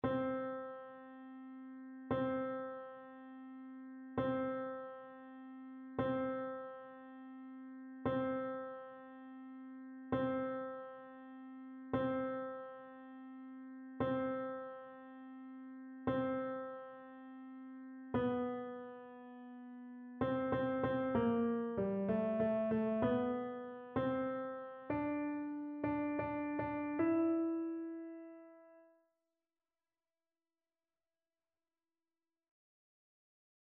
TénorBasse
annee-abc-fetes-et-solennites-presentation-du-seigneur-psaume-23-tenor.mp3